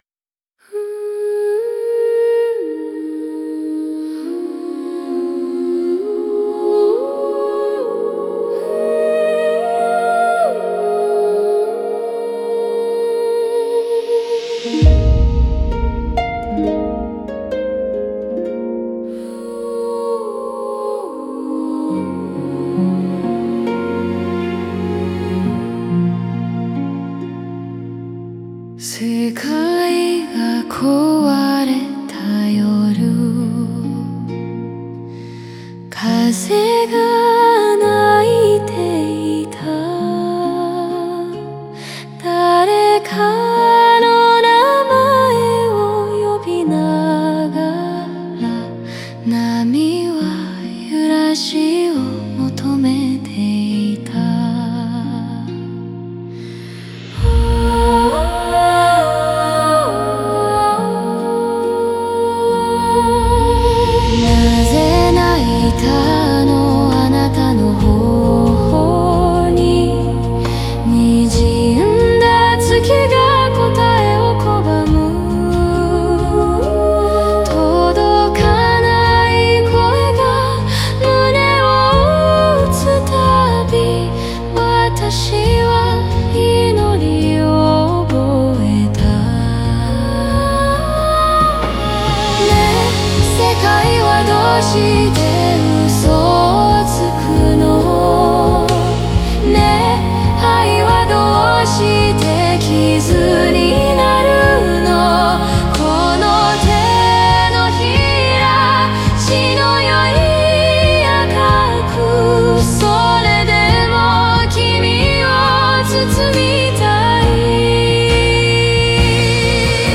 オリジナル曲♪
過去の痛みや怒り、赦しへの渇望が、祈りのようなリズムとともに流れ出す。
声の揺らぎや沈黙さえも感情の一部として響き、悲しみの中にある再生、破壊の先にある浄化を感じさせる。